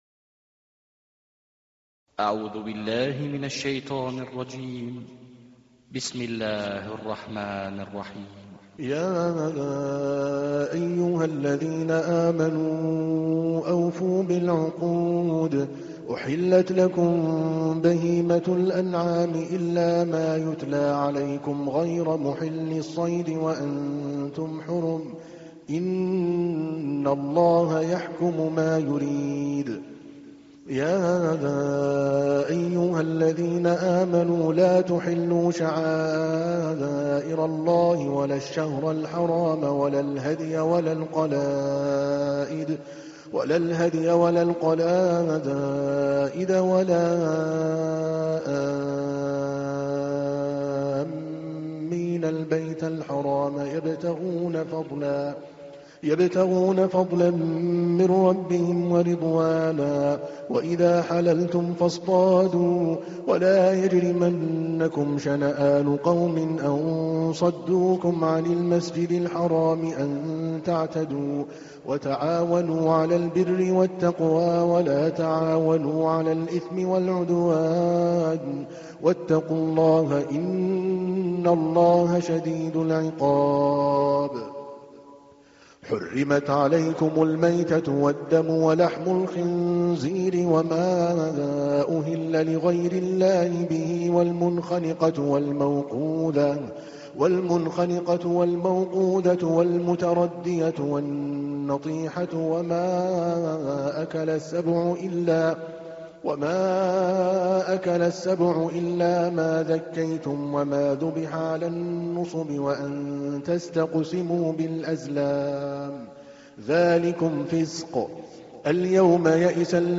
تحميل : 5. سورة المائدة / القارئ عادل الكلباني / القرآن الكريم / موقع يا حسين